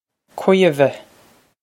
Caoimhe Kweev-uh
Pronunciation for how to say
This is an approximate phonetic pronunciation of the phrase.